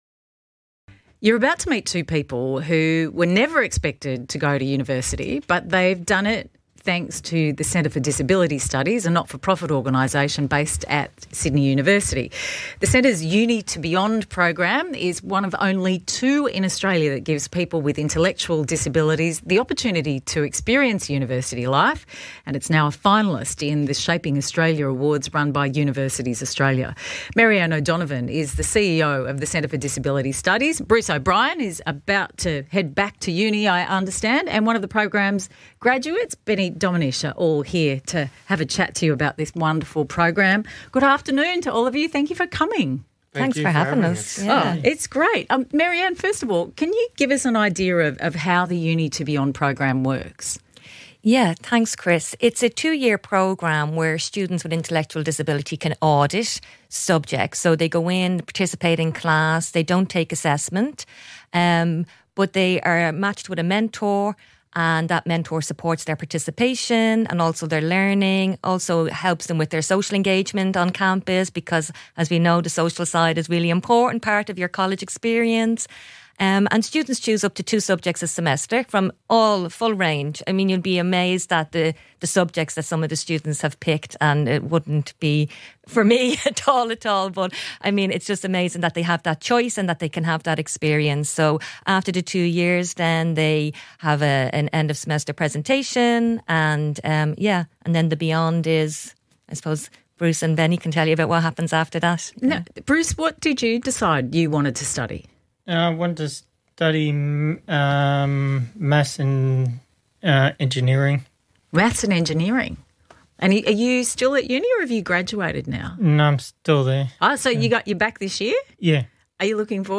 ABC-u2b-Radio-Interview.mp3